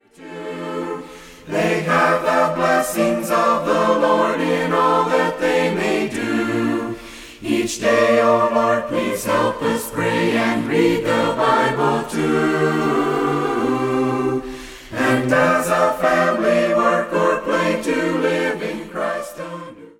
A cappella mixed group sings many beautiful hymns.